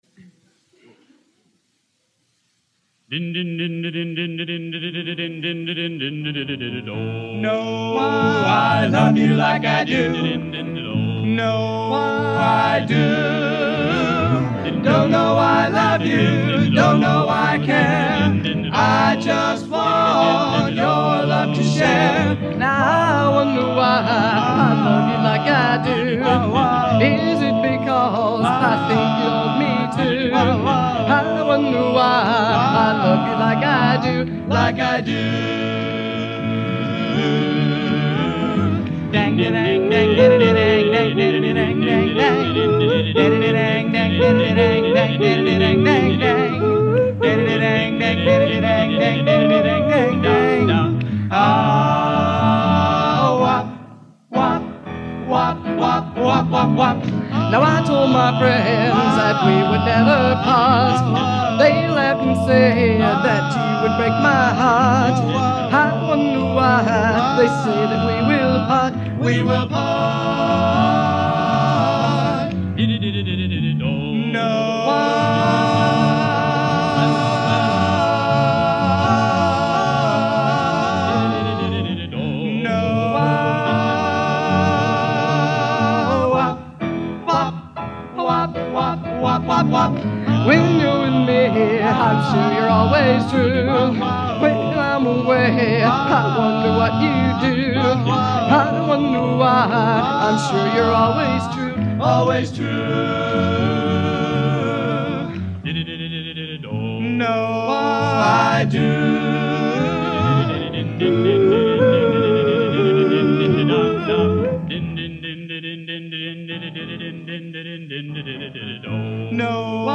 Genre: Doo Wop | Type: End of Season